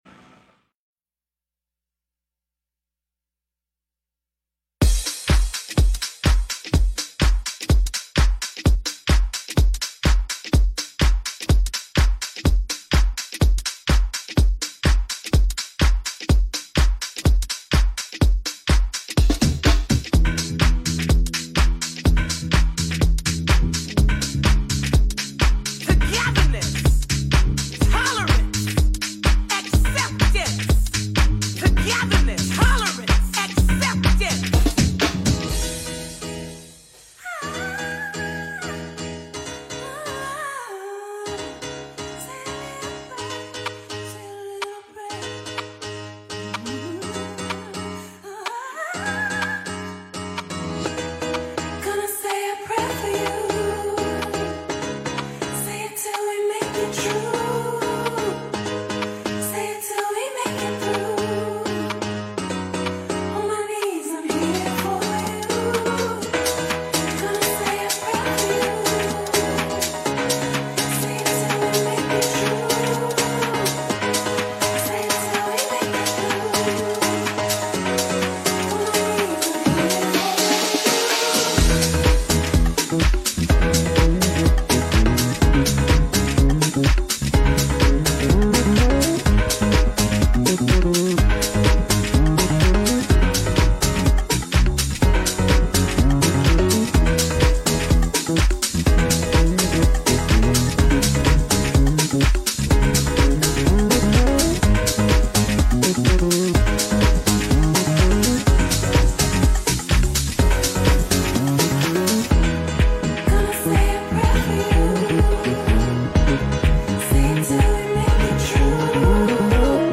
DJ Mixes and Radio Show